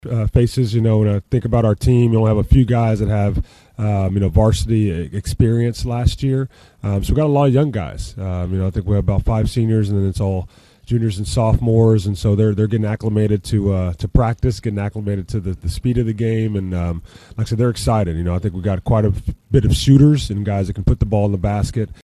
If you missed it from the weekend, we had our annual Coach’s Breakfast Broadcast from Chick-Fil-A in Bartlesville on Saturday morning, where we previewed high school basketball across Northeast Oklahoma with most every high school coach on hand to preview the upcoming season.